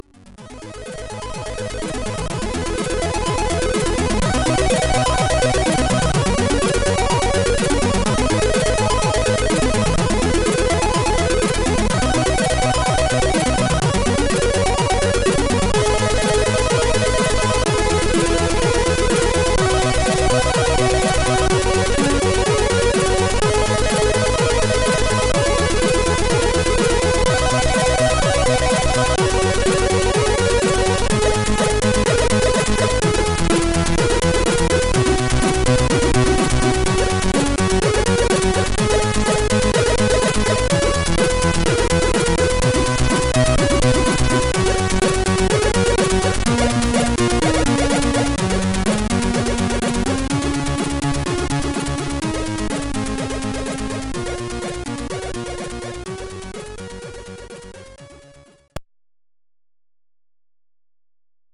Manchmal waren das nur "Piepstöne" usw.
Das hier ist das original vom Commodore 64:
C64 Original Musik.mp3